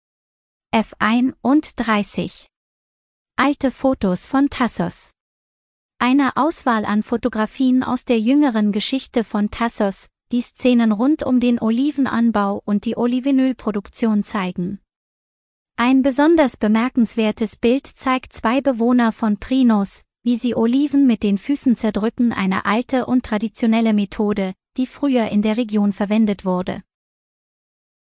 Geführte Audio-Tour